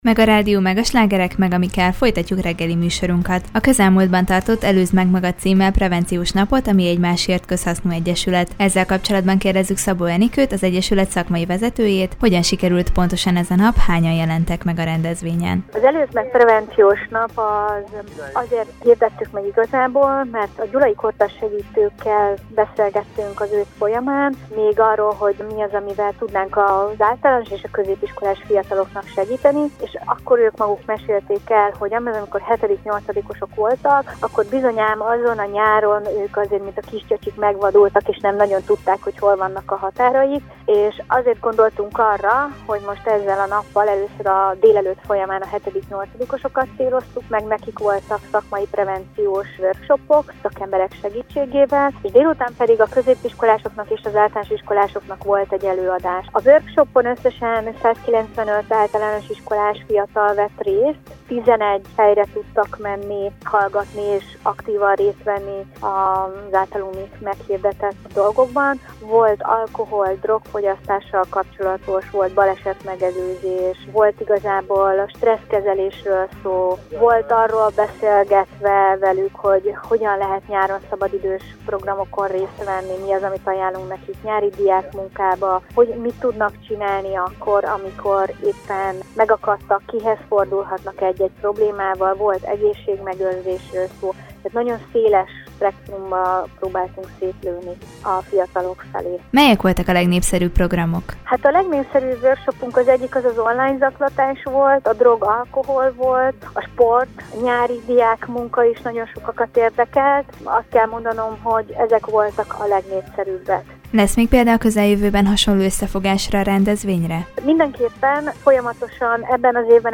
Általános- és középiskolás fiataloknak rendezték meg az Előzd meg magad prevenciós napot Gyulán. A prevenciós nap programjairól és indulásáról beszélgetett tudósítónk